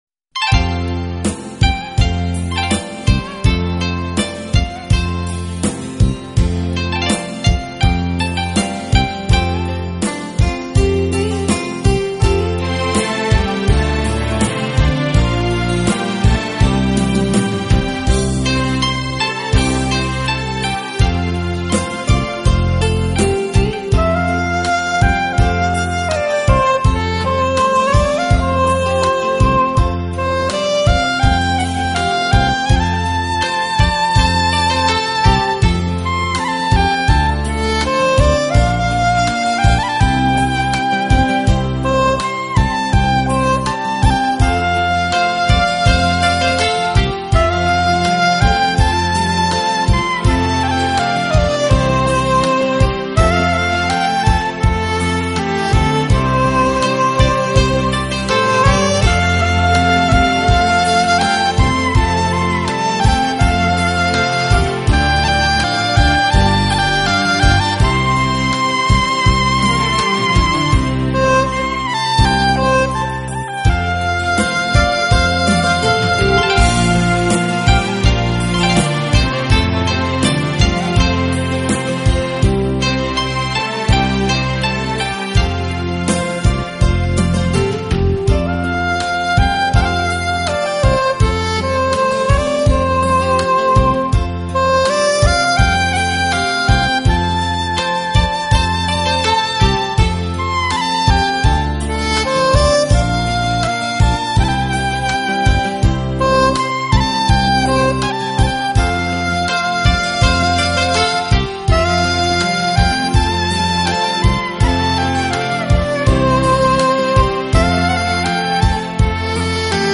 我的爱因你而存在缠绵的萨克斯乐曲，演绎浪漫的情人物语，
这丝丝情意像恋人般彼此依偎，轻轻地，静静地直到永远！